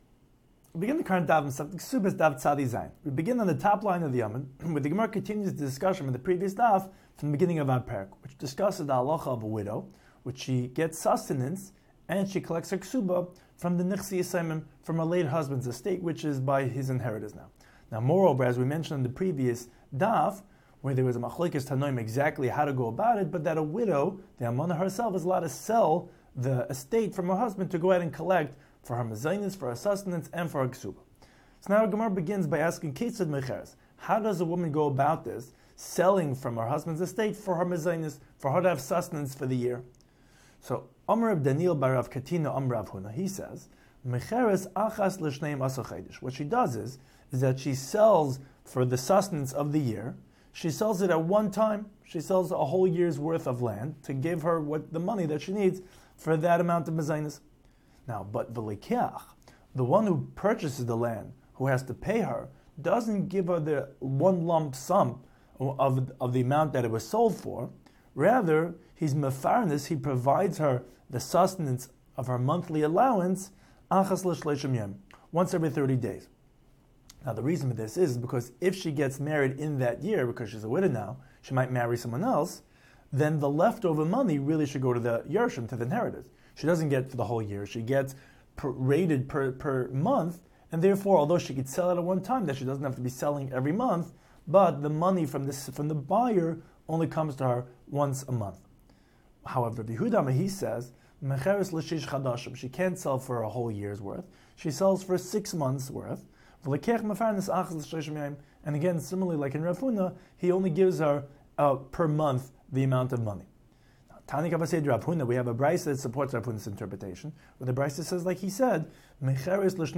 Daf Hachaim Shiur for Kesuvos 97